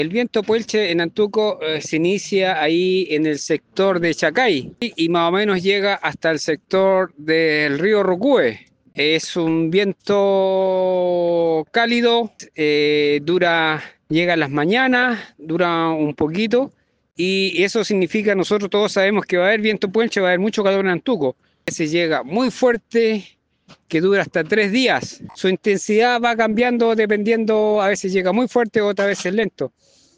Respecto a los efectos del viento Puelche cordillerano de la zona, La Radio consultó más detalles al arriero de Antuco